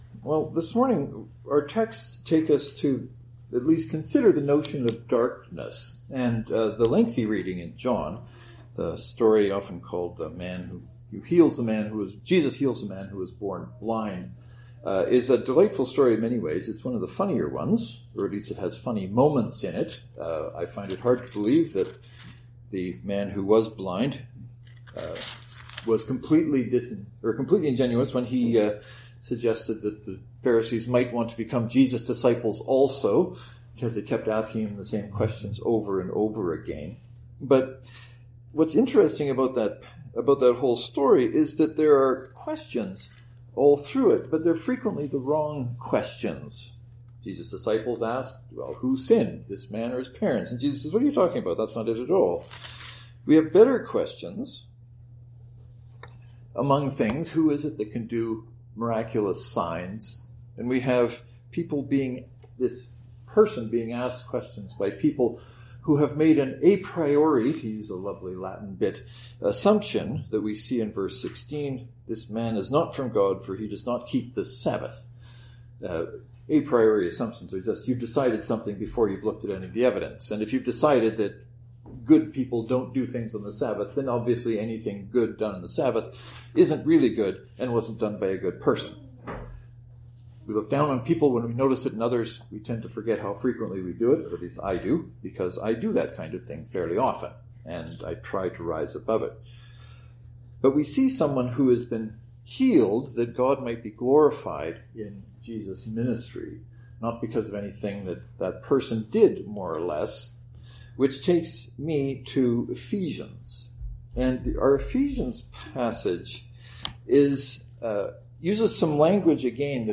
The sermons this week are my attempt at answering the question.